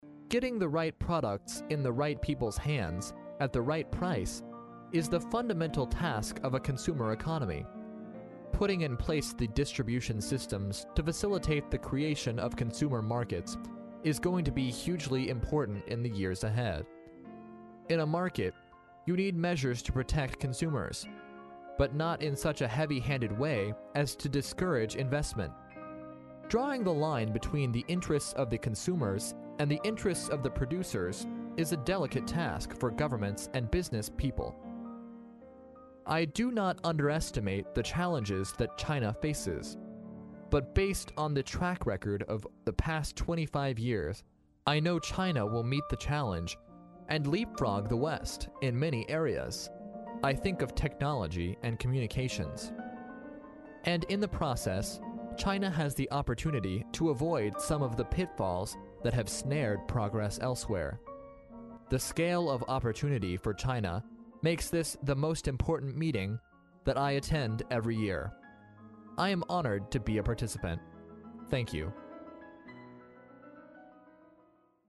在线英语听力室历史英雄名人演讲 第37期:一个潜力巨大的消费者市场(2)的听力文件下载, 《历史英雄名人演讲》栏目收录了国家领袖、政治人物、商界精英和作家记者艺人在重大场合的演讲，展现了伟人、精英的睿智。